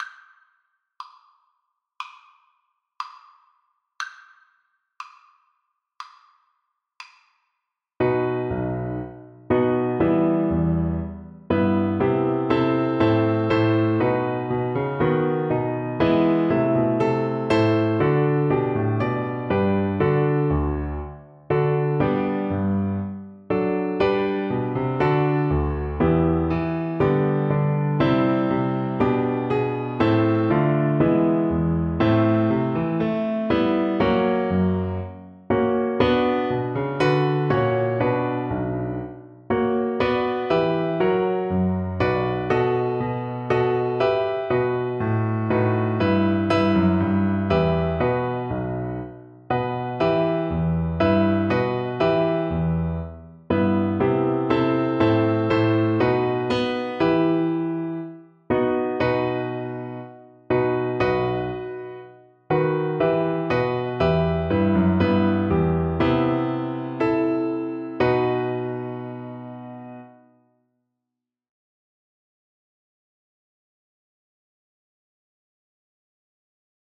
Flute
E minor (Sounding Pitch) (View more E minor Music for Flute )
4/4 (View more 4/4 Music)
Andante = c.60
Classical (View more Classical Flute Music)